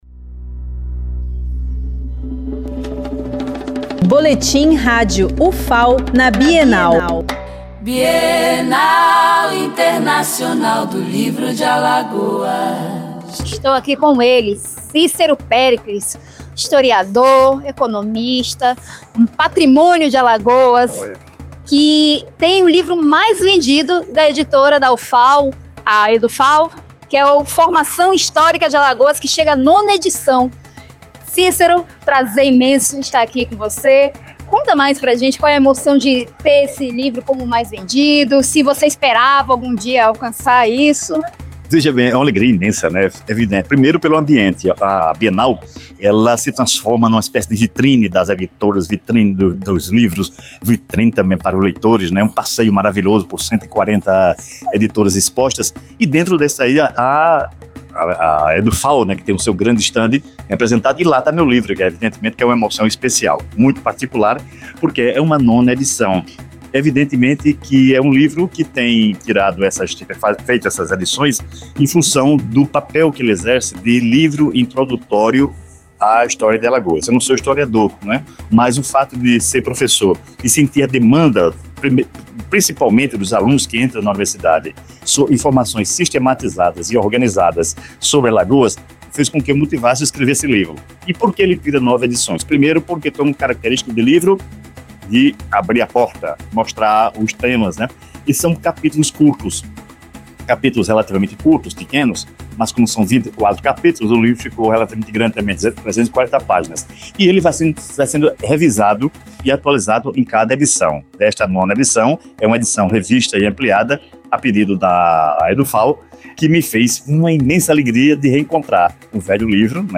Flashes com informações da 11ª Bienal Internacional do Livro de Alagoas, realizada de 31 de outubro a 9 de novembro de 2025